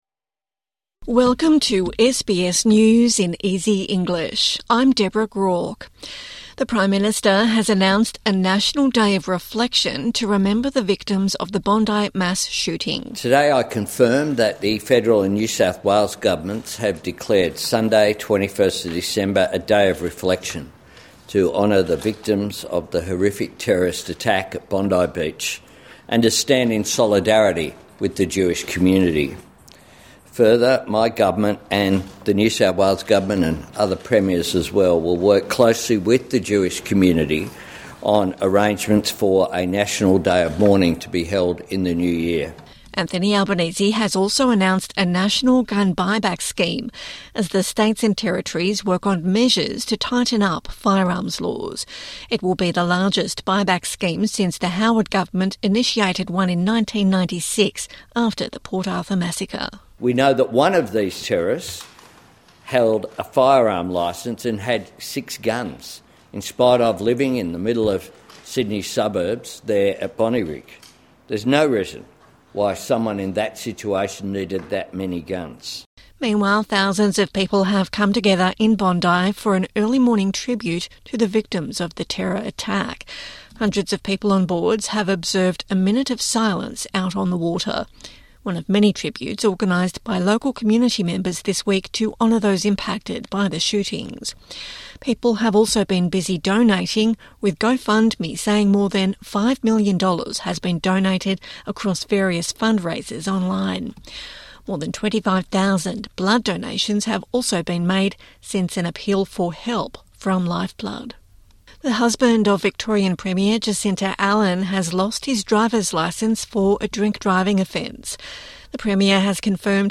A daily 5-minute news wrap for English learners and people with disability.